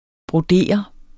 Udtale [ bʁoˈdeˀʌ ]